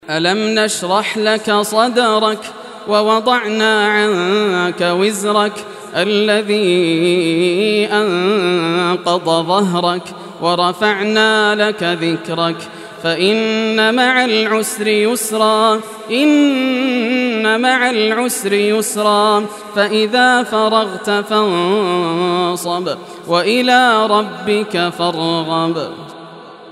Surah Ash-Sharh Recitation by Yasser al Dosari
Surah Ash-Sharh, listen or play online mp3 tilawat / recitation in Arabic in the beautiful voice of Sheikh Yasser al Dosari.